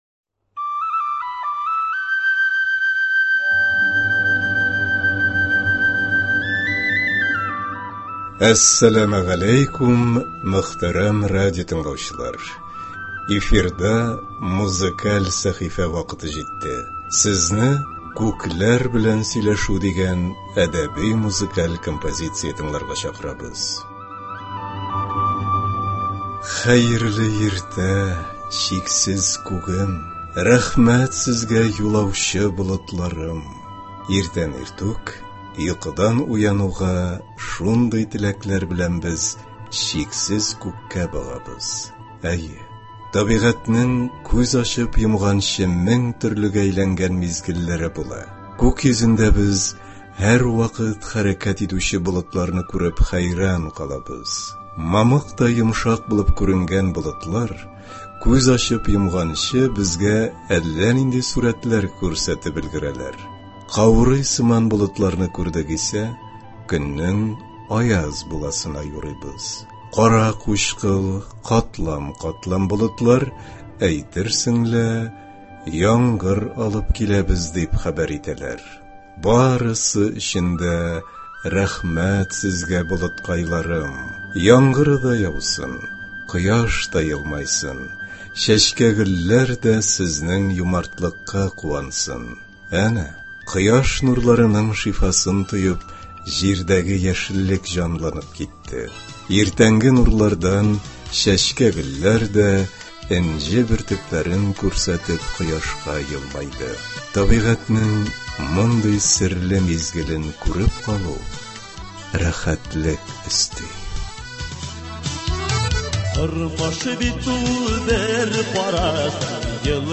Сезне “Күкләр белән сөйләшү” дигән әдәби-музыкаль композиция тыңларга чакырабыз.